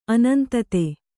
♪ anantate